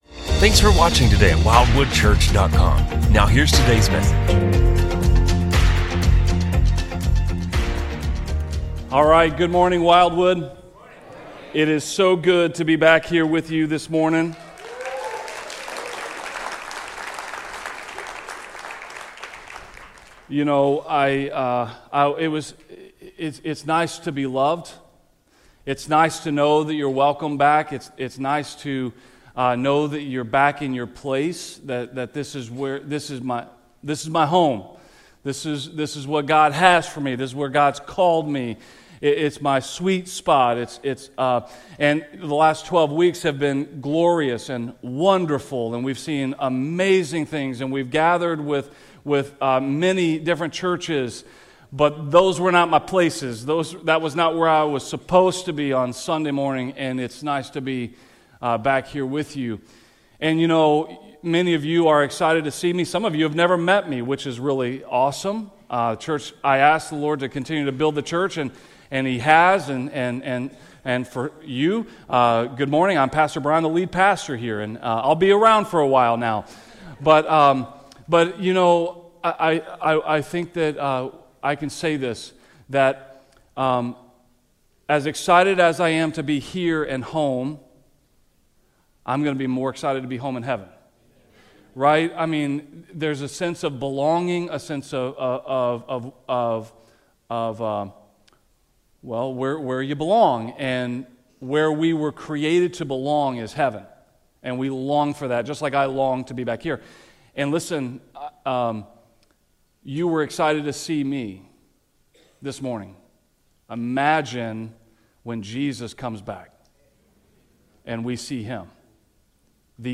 A message from the series "Behold the Glory." What glory of Christ do we behold by faith today?